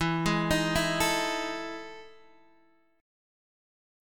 EM11 Chord
Listen to EM11 strummed